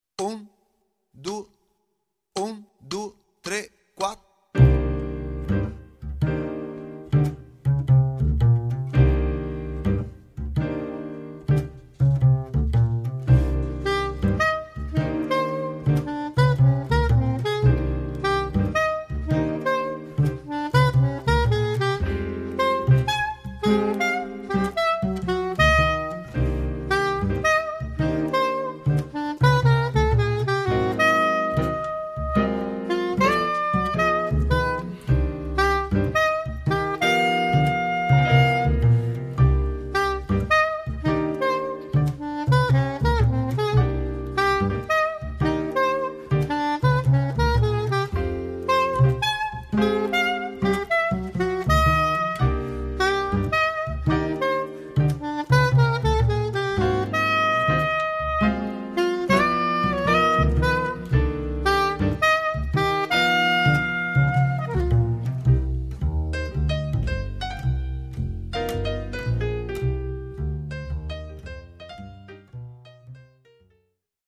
La struttura metrica ed armonica di questo brano è quella di un blues in 12 battute, forma molto comune nella tradizione jazzistica. In questo caso, il blues, è in tonalità minore.
"B" è la sezione dei soli, 3 chorus di pianoforte e 3 di sax soprano.
Tutto il tema si snoda su una sovrapposizione ritmica di 3/4 (od anche 3/8) su 4/4 suonata dalla sezione ritmica.
File Audio no drums (1.2 MB)